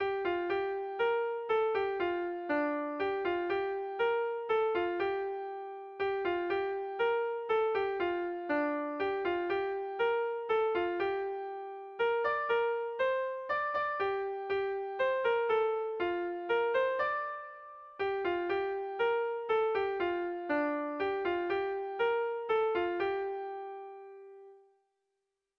Erlijiozkoa
Zortziko ertaina (hg) / Lau puntuko ertaina (ip)
AABA